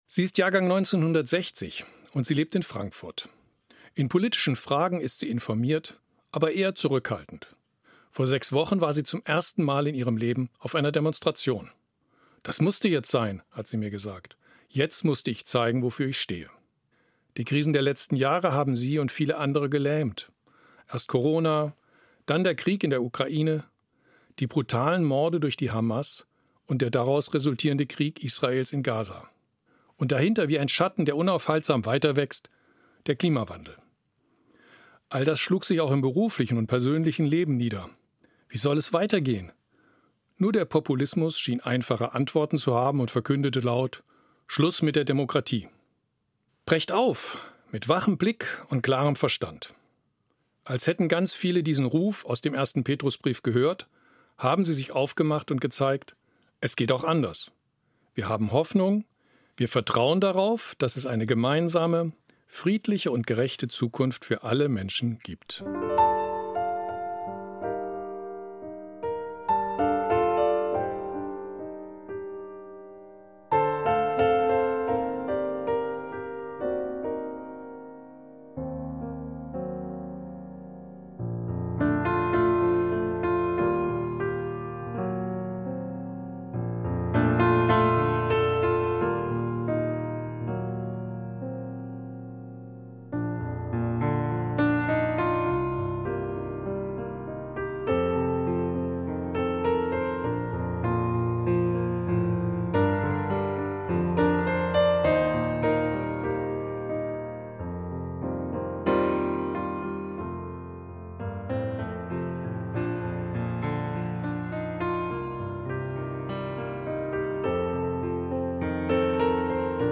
Eine Sendung von Dr. Jochen Cornelius-Bundschuh, Evangelischer Pfarrer, Kassel